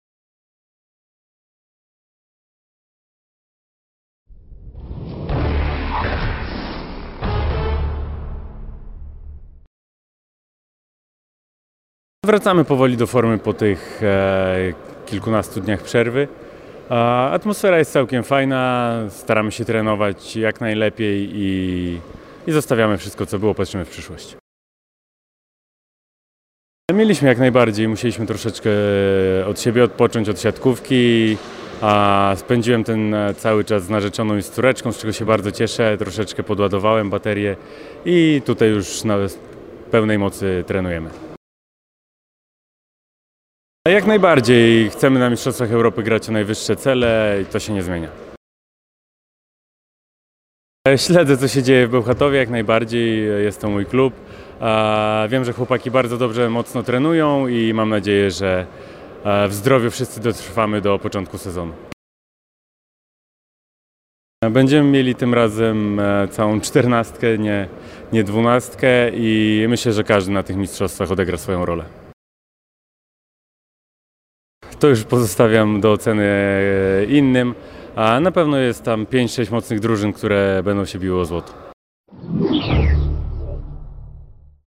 – W mistrzostwach Europy chcemy grać o najwyższe cele. Tutaj nic się nie zmienia – mówi Grzegorz Łomacz, rozgrywający PGE Skry, który powalczy z reprezentacją Polski o złoto w EuroVolley 2021.